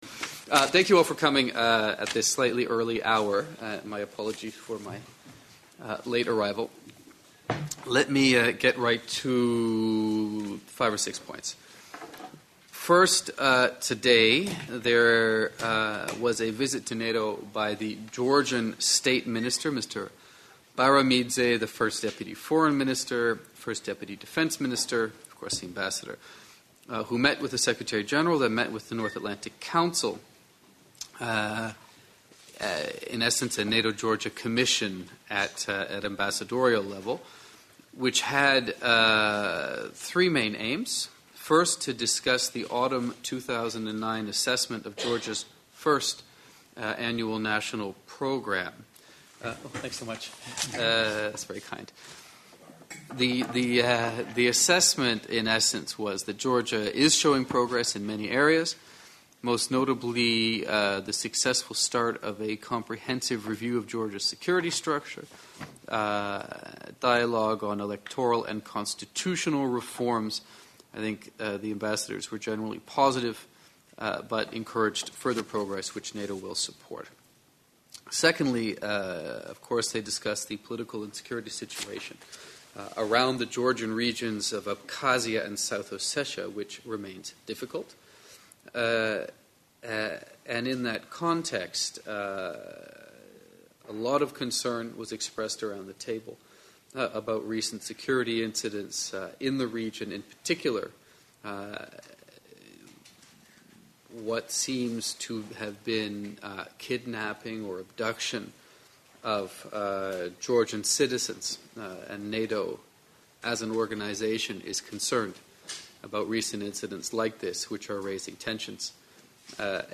Weekly press briefing by NATO Spokesman, James Appathurai - 18 November 2009